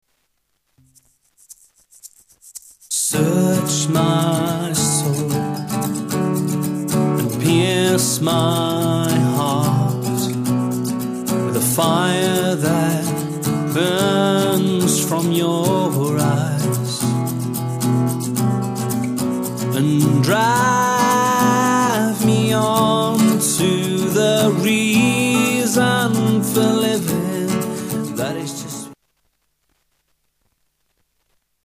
Style: Rock Approach: Praise & Worship